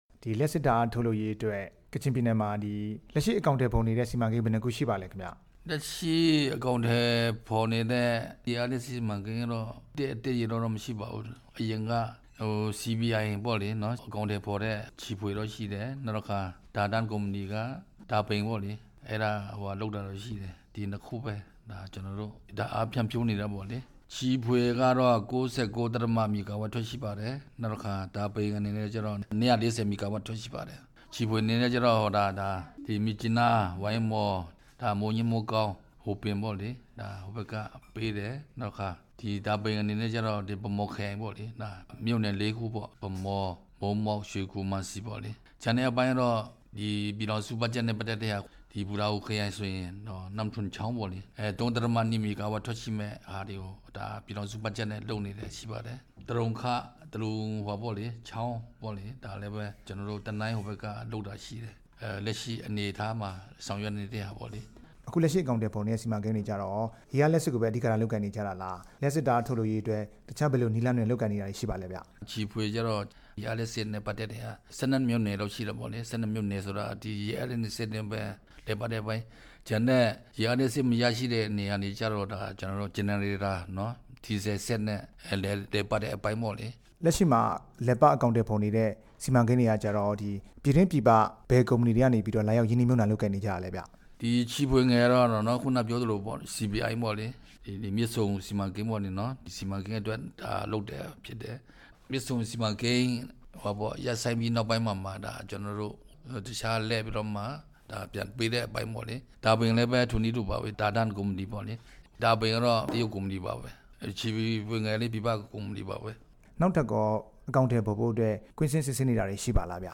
ကချင်ပြည်နယ်လျှပ်စစ်နဲ့ စက်မှုလက်မှုဝန်ကြီး ဦးစိုင်းမောင်ရွှေနဲ့ မေးမြန်းချက်